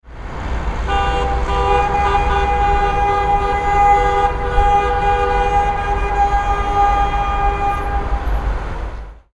Car Horn Honk Sound Effect
Description: Car horn honk sound effect. The sound of a car siren on a bustling city road. Street noises.
Car-horn-honk-sound-effect.mp3